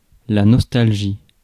Ääntäminen
Ääntäminen France: IPA: [nɔs.tal.ʒi] Haettu sana löytyi näillä lähdekielillä: ranska Käännös Substantiivit 1. носталгия {f} (nostalgija) Suku: f .